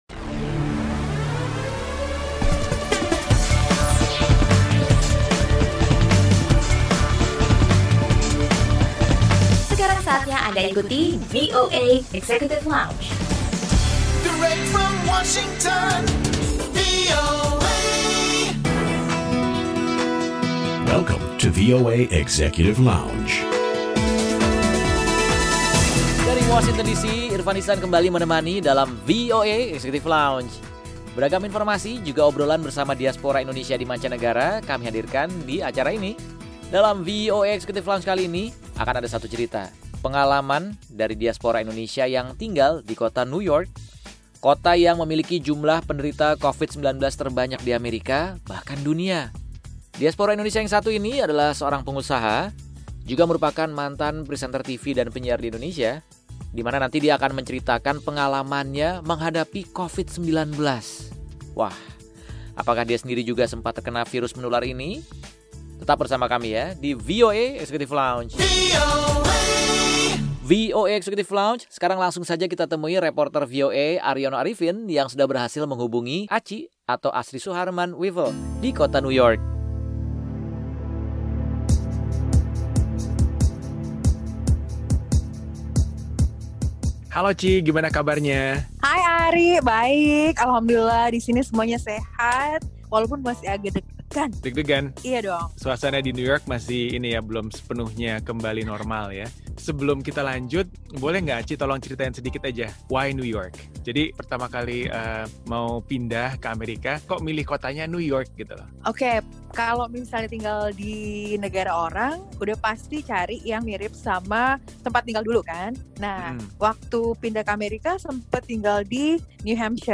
Obrolan bersama diaspora Indonesia di kota New York, seputar pengalamannya tinggal di kota dengan jumlah penderita COVID terbanyak di Amerika.